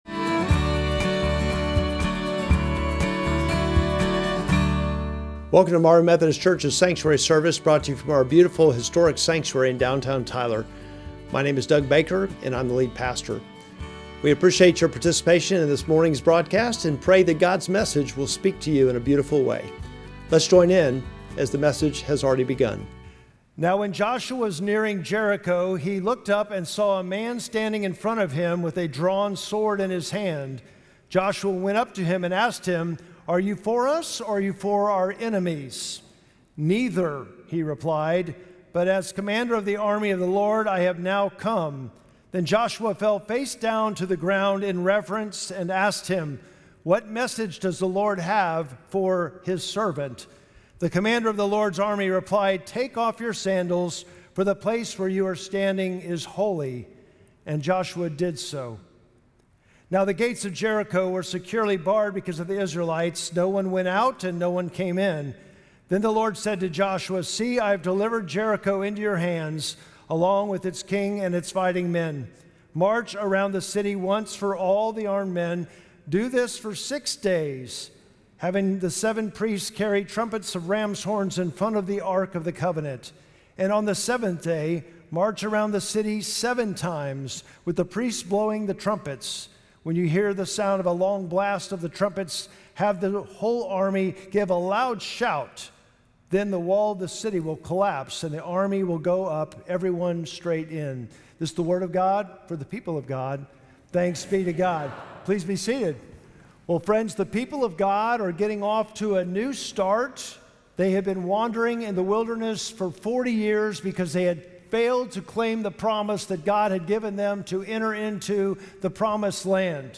Sermon text: Joshua 5:13-6:5